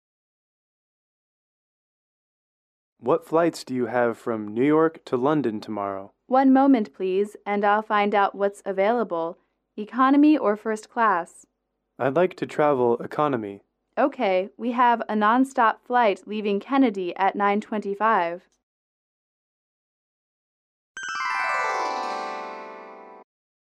英语口语情景短对话15-1：询问航班(MP3)